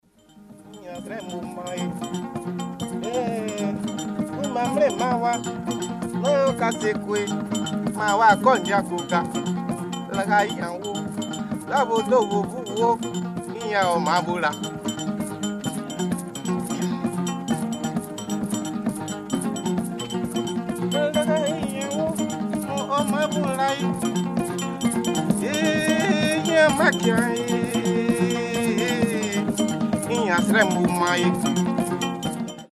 Harpe Ngombi, population ngbaka-ma’bo, République Centrafricaine.
864-Harpe-ngombi-Centrafrique.mp3